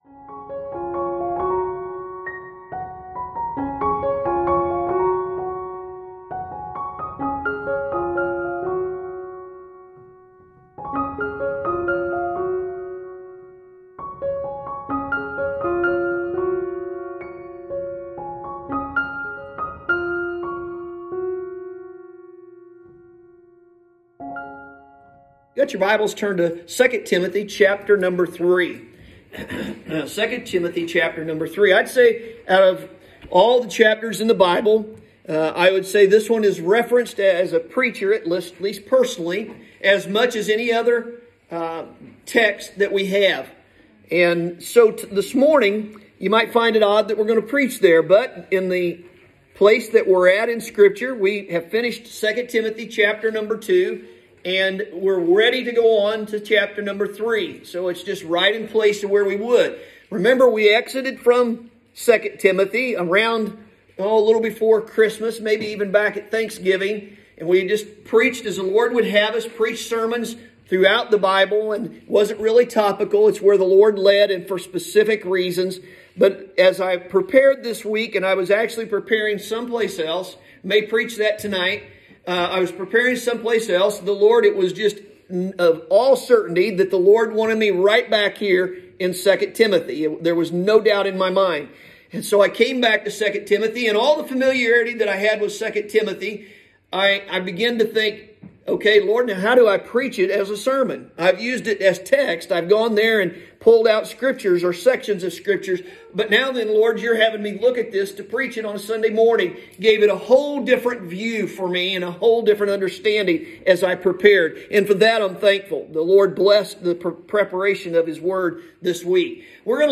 Sunday Morning – January 17, 2021